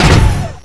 pl_fallpain3-2.wav